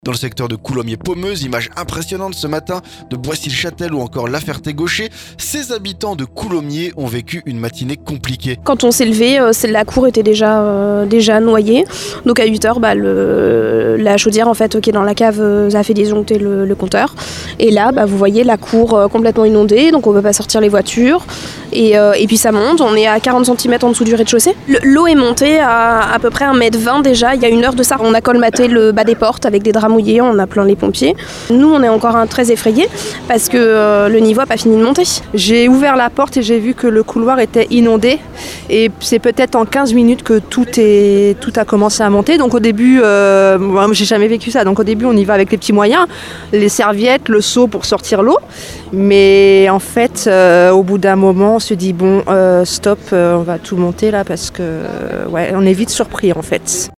INONDATIONS - Reportage à Coulommiers
Reportage auprès des sinistrés de la crue du Grand Morin. Après Coulommiers et Crécy jeudi, le niveau augmente encore à Couilly-pont-aux-dames et Condé-sainte-libiaire ce vendredi.